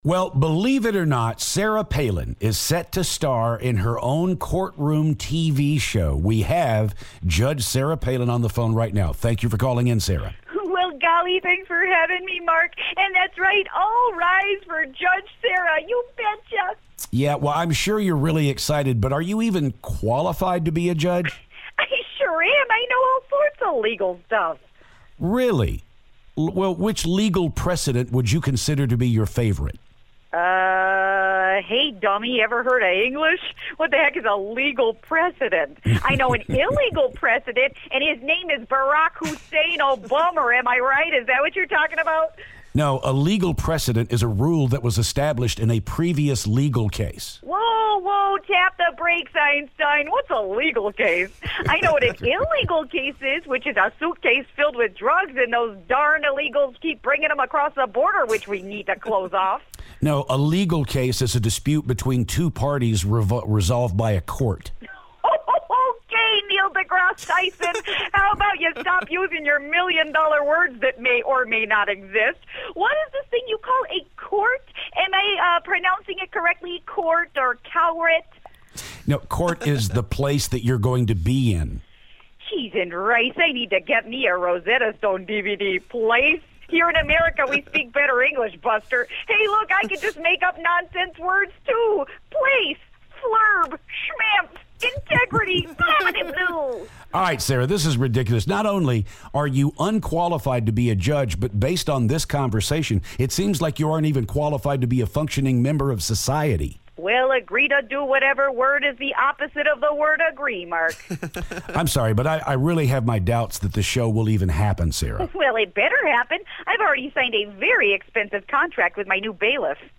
Sarah Palin Phoner
Sarah Palin calls to talk about her new courtroom show!